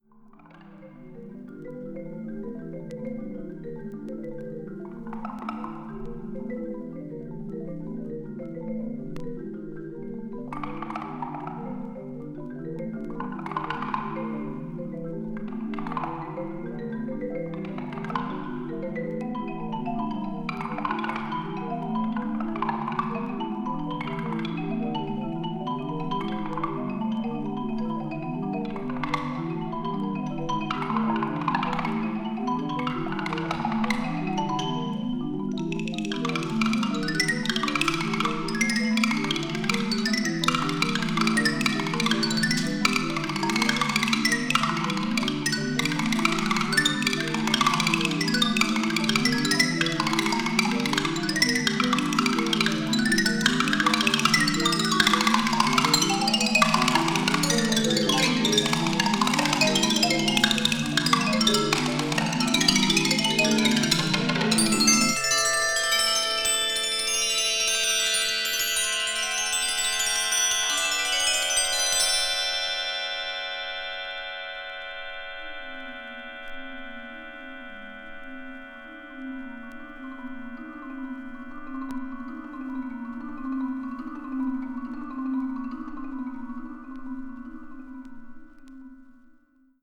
media : EX/EX(わずかにチリノイズが入る箇所あり)
A面は水滴のように滴り落ちるマリンバの打撃音やさまざまな鳴りものが心地良く鳴り響きます。
いずれも静と動の抑揚のある展開が素晴らしい、ポスト・アンビエント感覚で聴きたい楽曲です。
20th century   contemporary   percussion solo   post modern